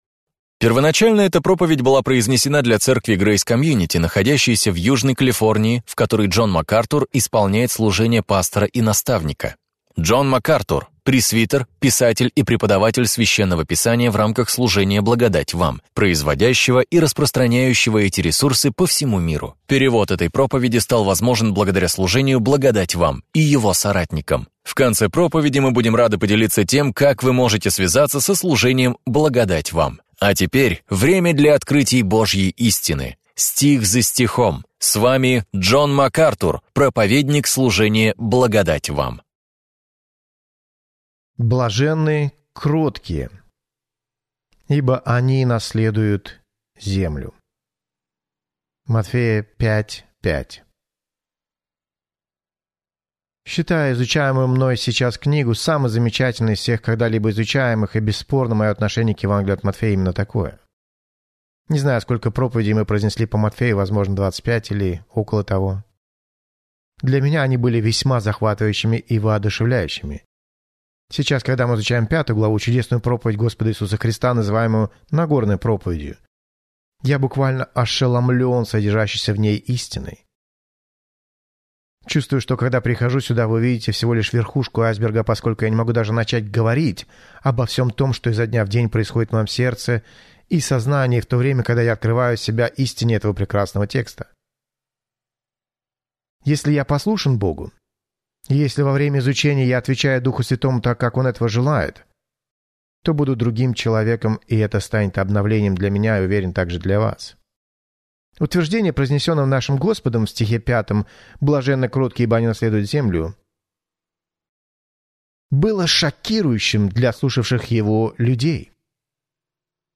В этой проповеди вы узнаете: дорога вниз является дорогой вверх; голод и жажда ведут к удовлетворению; духовная бедность ведет к духовному богатству; скорбь – это путь к радости; нищета – это путь к обладанию всем.